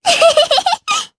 Lilia-Vox_Happy3_jp.wav